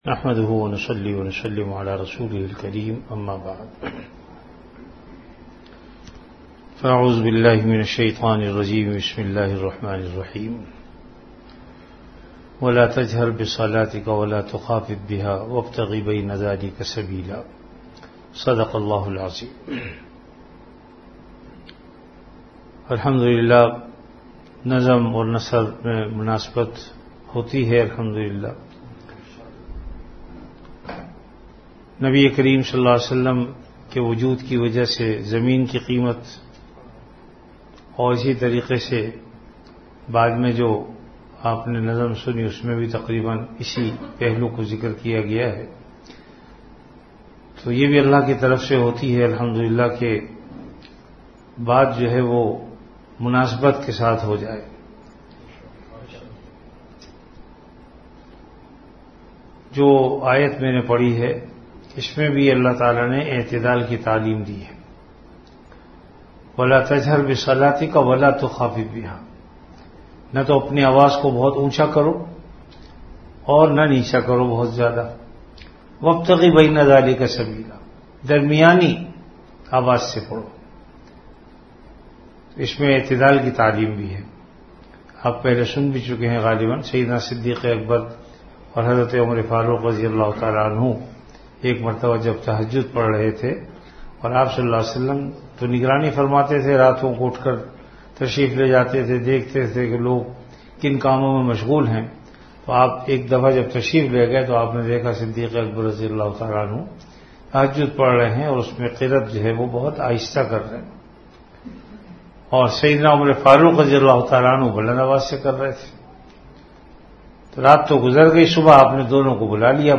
An Islamic audio bayan
Delivered at Home.
Majlis-e-Zikr